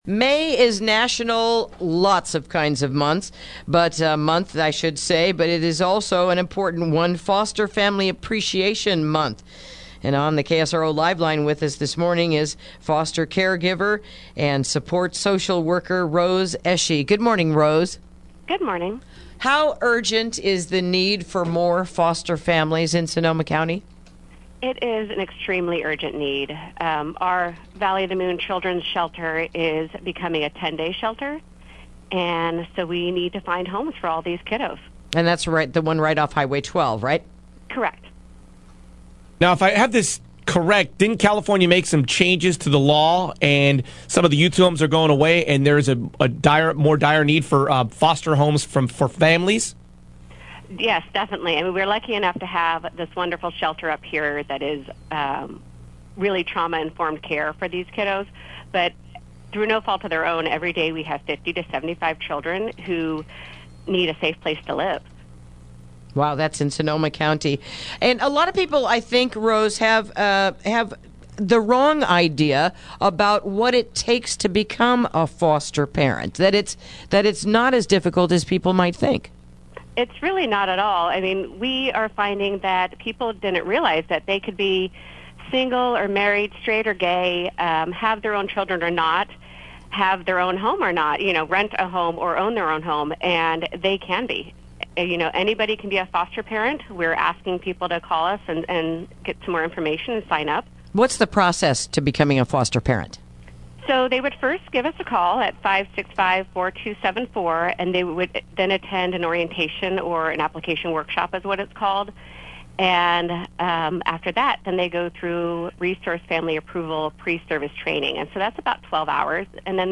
Interview: It's Foster Family Appreciation Month | KSRO 103.5FM 96.9FM & 1350AM